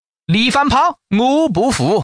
Index of /mahjong_xianlai/update/12943/res/sfx/changsha_man/